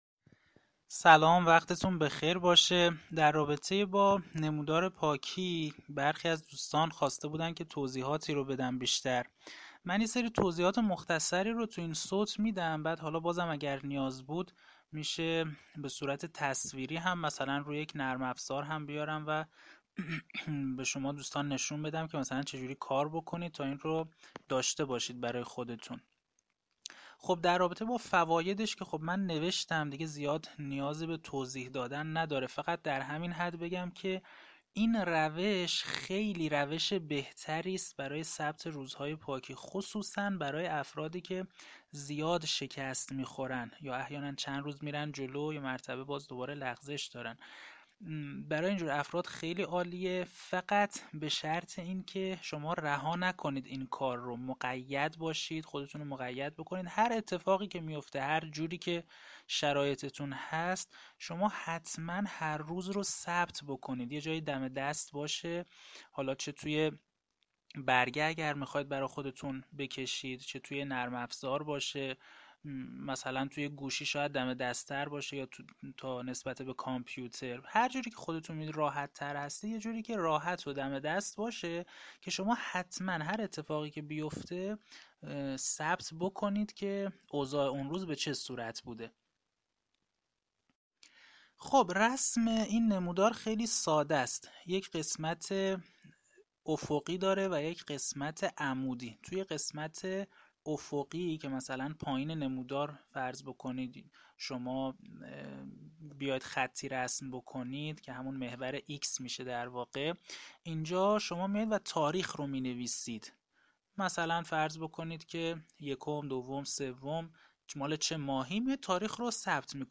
آموزش صوتی رسم نمودار